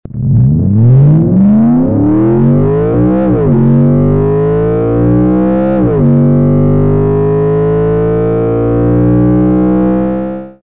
QUATTROPORTE INTERIOR - World's best active sound